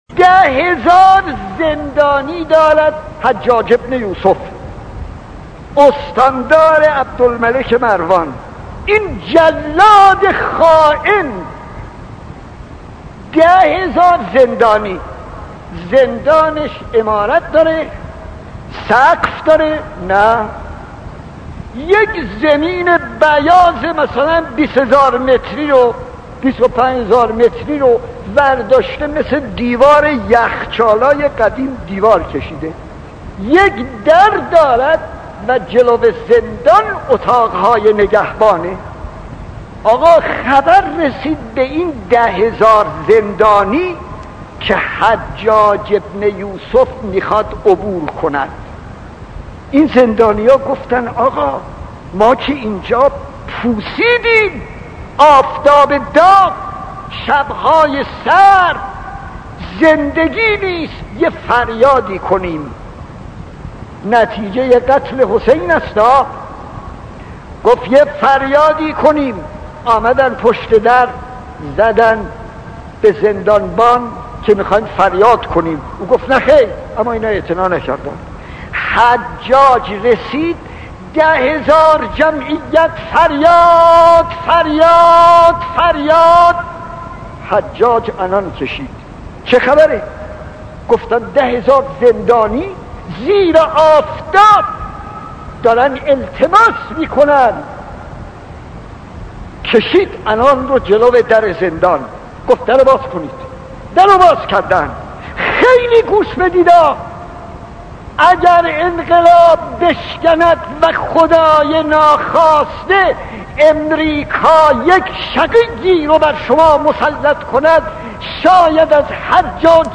داستان 23 : حجاج ابن یوسف خطیب: استاد فلسفی مدت زمان: 00:03:11